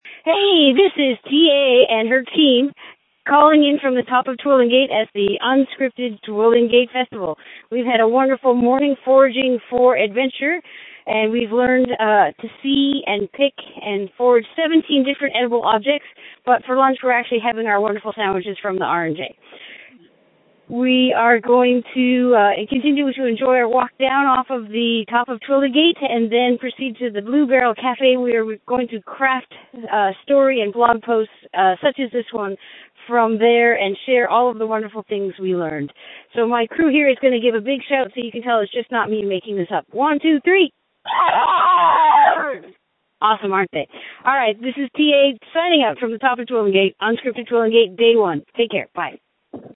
Audio Post from the Top of Twillingate